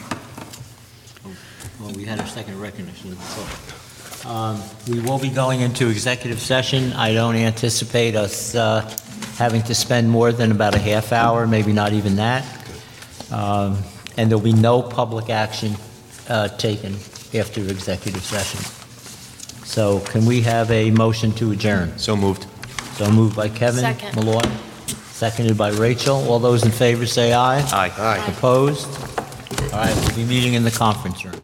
The audio excerpts below are un-edited clips from the official school board recordings, downloaded from the school district’s website.